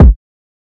Kick 8.wav